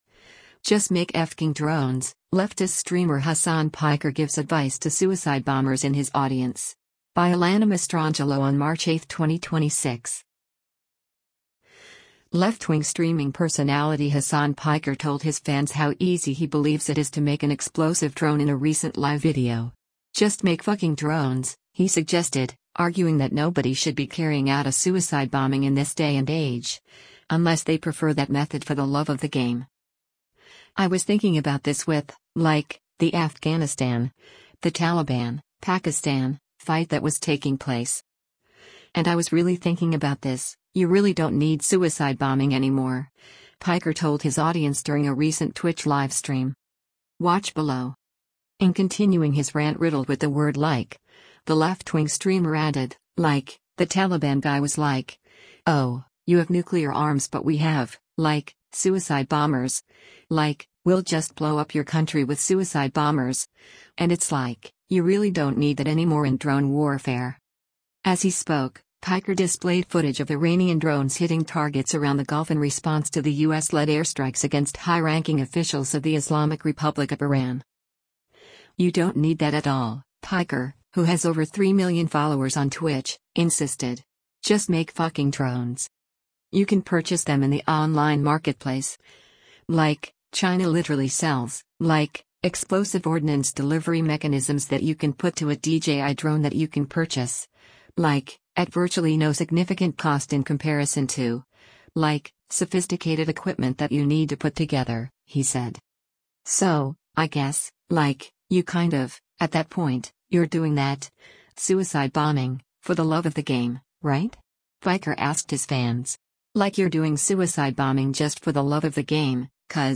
Left-wing streaming personality Hasan Piker told his fans how easy he believes it is to make an explosive drone in a recent live video.
“I was thinking about this with, like, the Afghanistan, the Taliban, Pakistan, fight that was taking place. And I was really thinking about this, you really don’t need suicide bombing anymore,” Piker told his audience during a recent Twitch livestream.